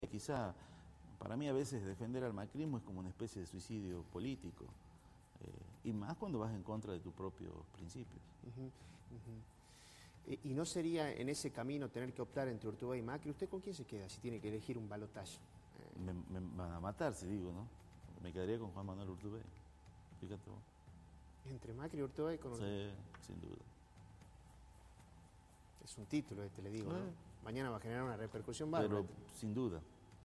El Diputado Provincial de la Unión Cívica Radical, Humberto “Ulúa” Vázquez, habló en el programa La Otra Campana que se emite por Cable Express y levantó polvareda.